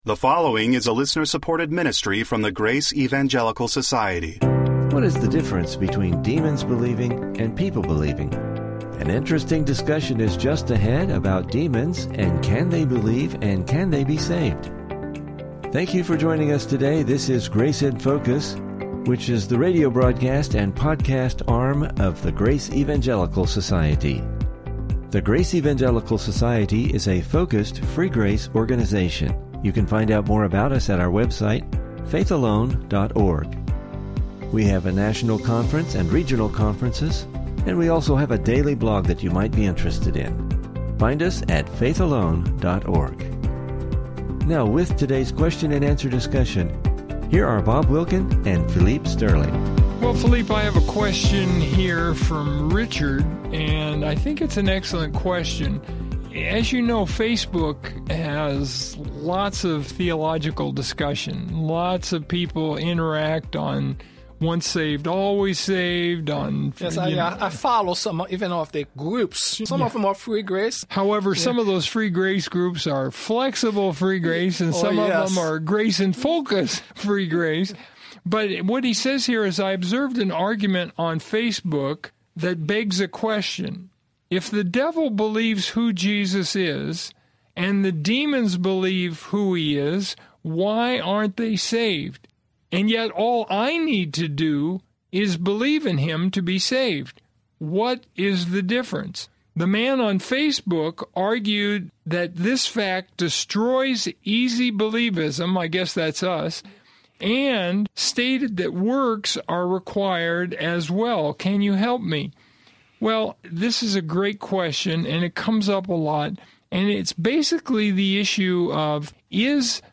Can demons be saved? What is the difference between demons believing and people believing? Please listen for some interesting Biblical discussion regarding this subject!